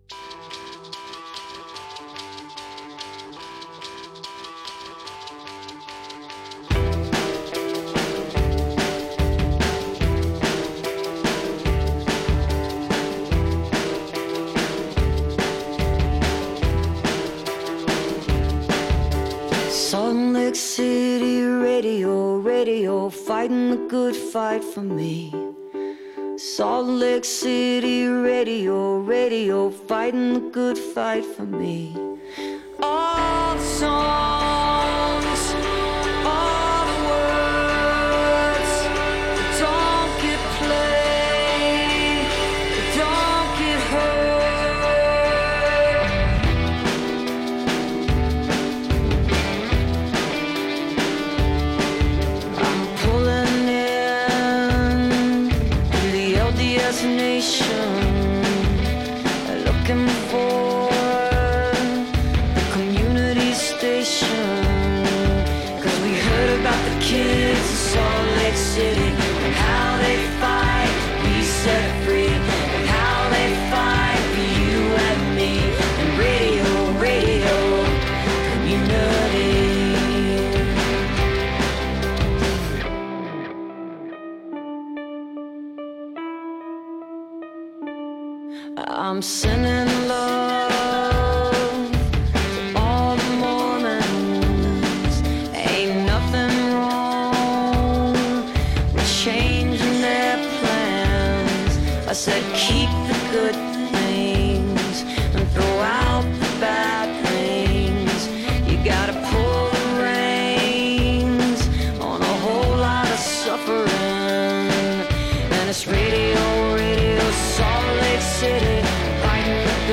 (recorded from webcast)